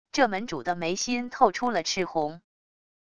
这门主的眉心透出了赤红wav音频生成系统WAV Audio Player